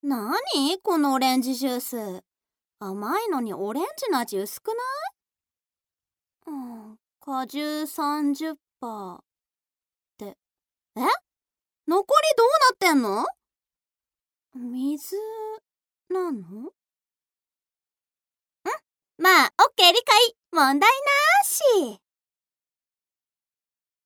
アトリエピーチのサンプルボイス一覧および紹介